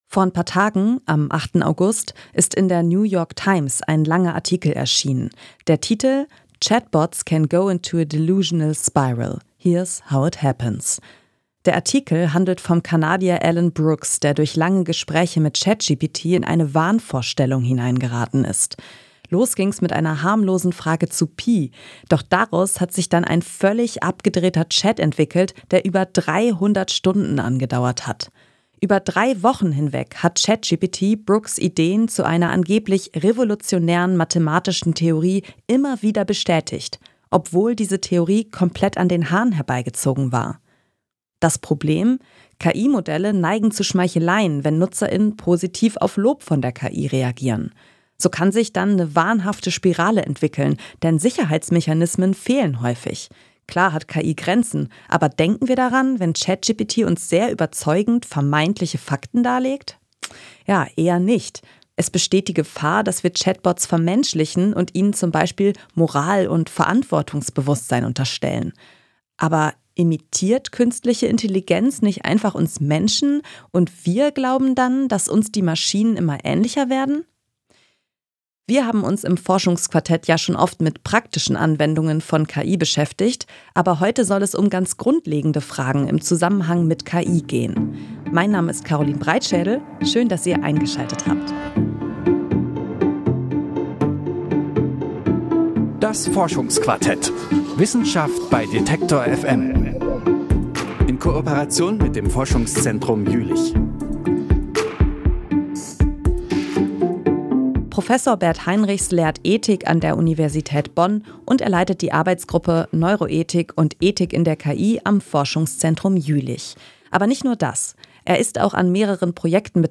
Können Maschinen moralische Verantwortung tragen? Ein Gespräch über KI und Ethik.